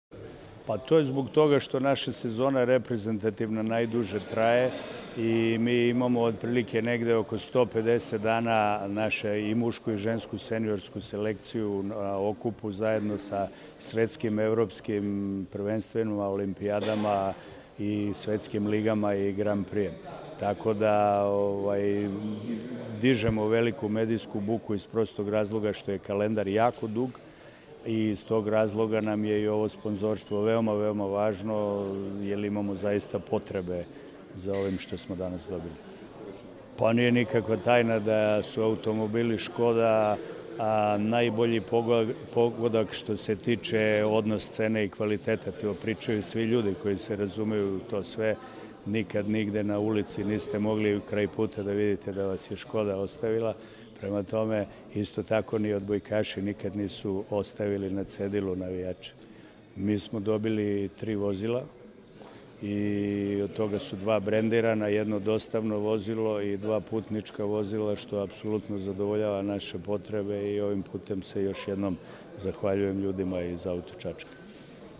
U prostorijama kompanije “Auto Čačak” na Novom Beogradu, danas je svečano potpisan Ugovor o saradnji između kompanije “Auto Čačak” i Odbojkaškog Saveza Srbije.
IZJAVA